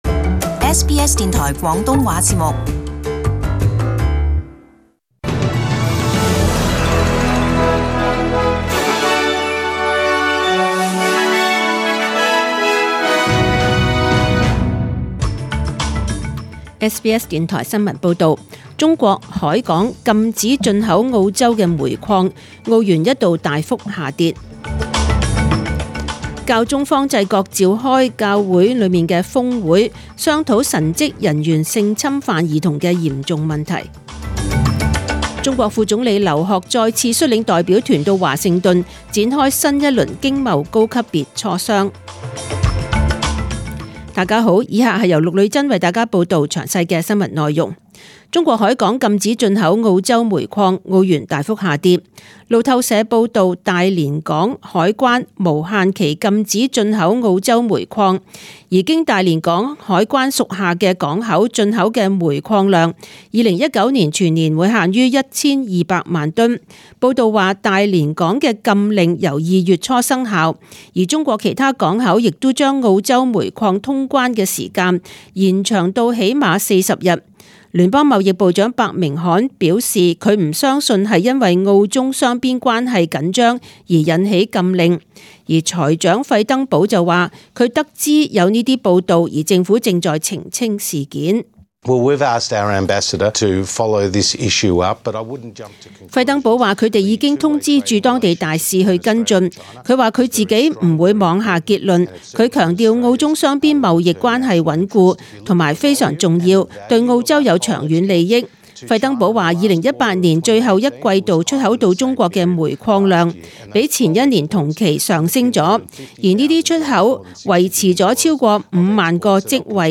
Chinese (Cantonese) News Source: SBS News